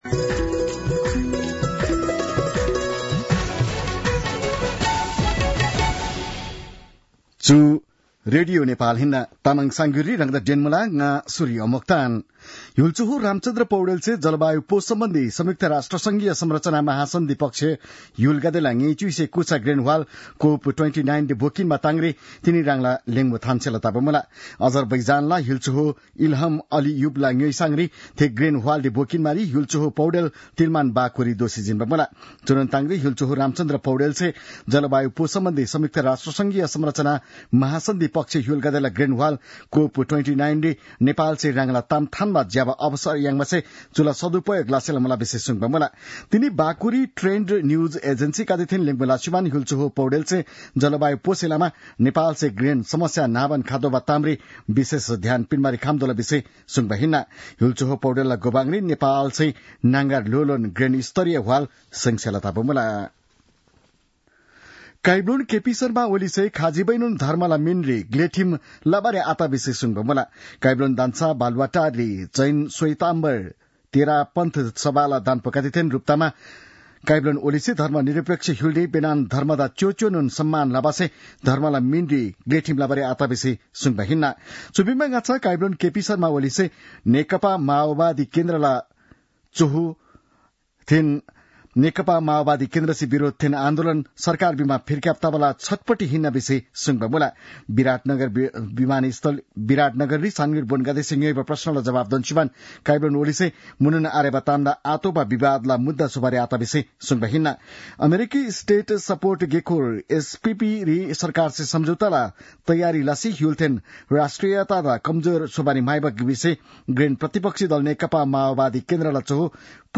तामाङ भाषाको समाचार : २८ कार्तिक , २०८१